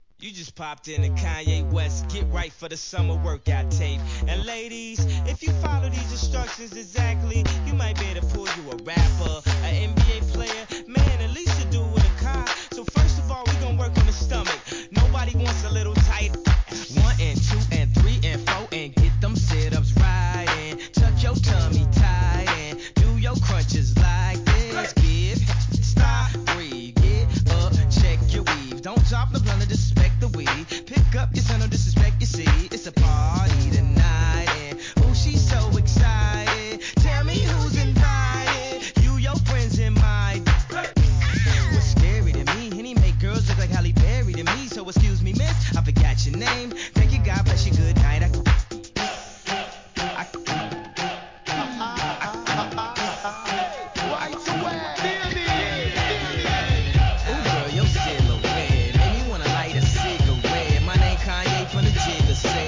B (A-1で周期的なノイズ。)
HIP HOP/R&B